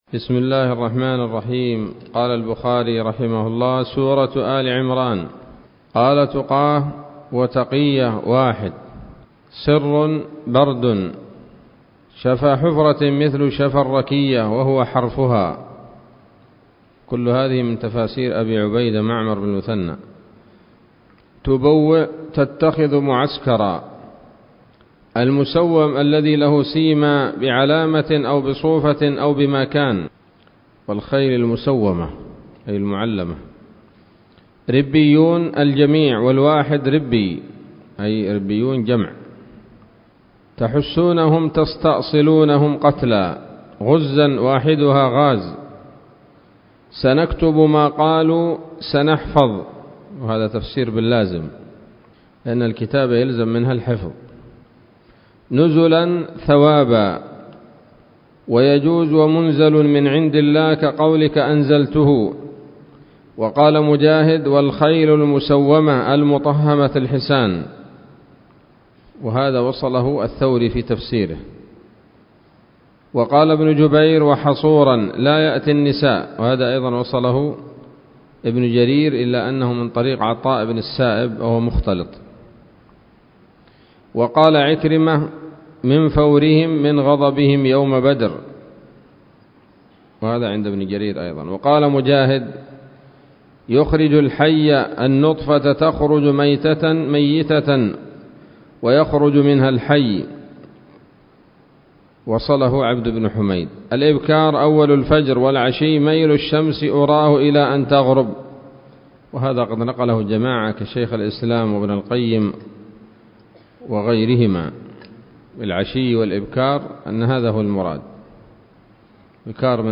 الدرس الخامس والأربعون من كتاب التفسير من صحيح الإمام البخاري